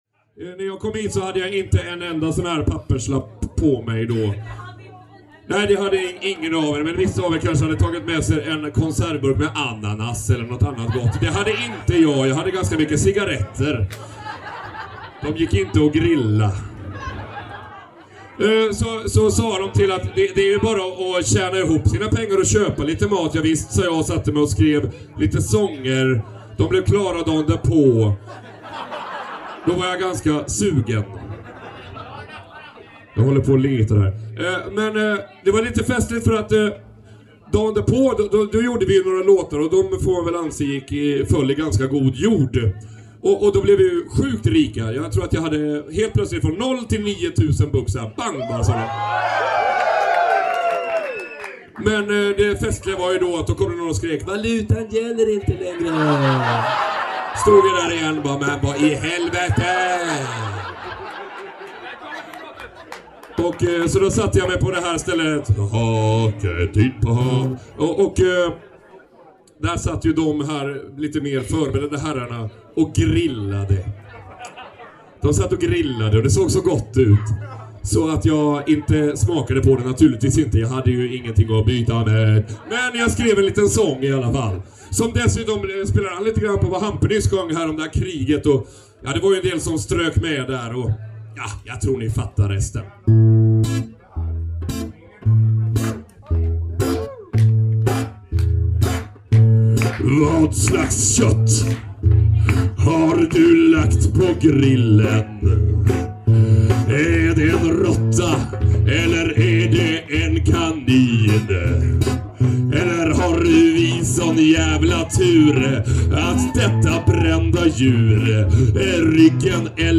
Visa